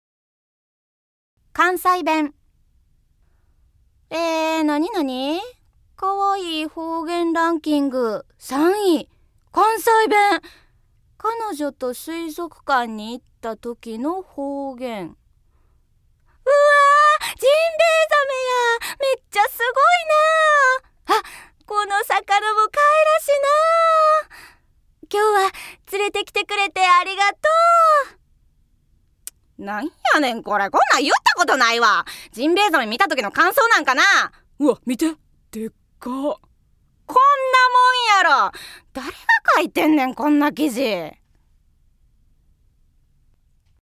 ◆関西弁◆